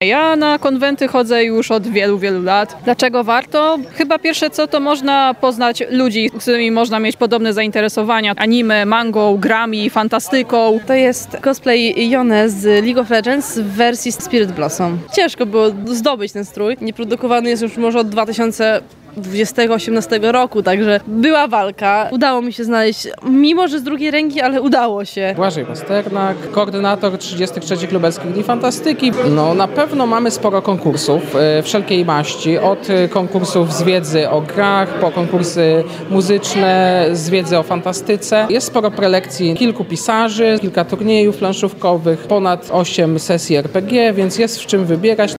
Miłośnicy szeroko pojętej fantastyki i gier planszowych spotkali się w Lublinie podczas 33. Lubelskich Dni Fantastyki.
Można poznać tu ludzi o podobnych zainteresowaniach – anime, manga, gry, fantastyka – mówią uczestnicy.